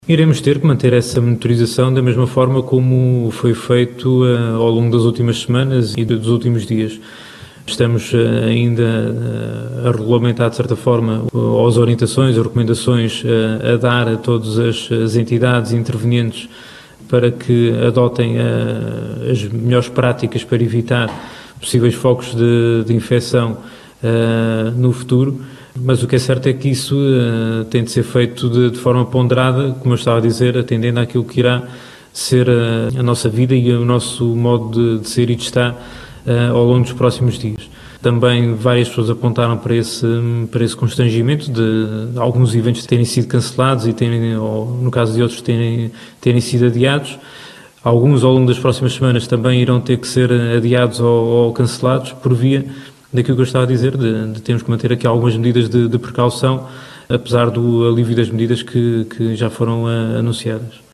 A Região conta com 80 casos positivos ativos, 763 suspeitos, 2.291 vigilâncias ativas, 49 recuperados e 14 óbitos. Os dados foram avançados, este sábado, pelo diretor regional da Saúde, em conferência de imprensa, em Angra do Heroísmo, no habitual briefing diário sobre a pandemia.